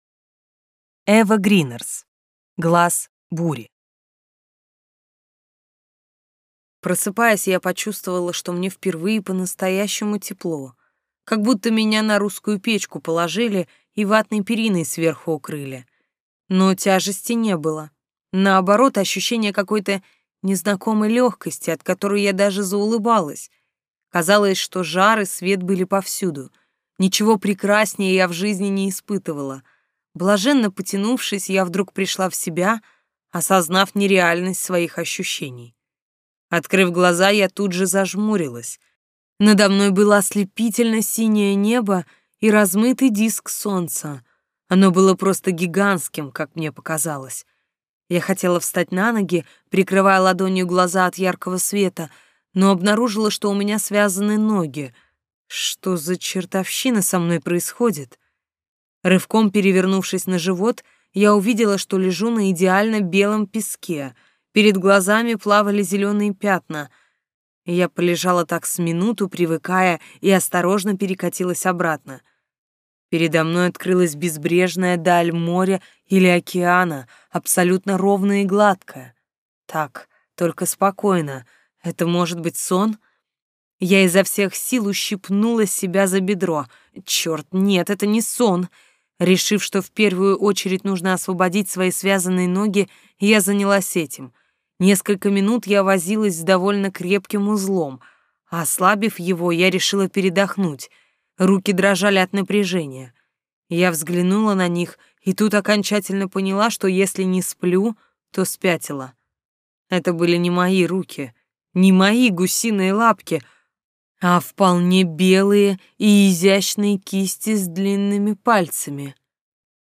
Аудиокнига Глаз бури | Библиотека аудиокниг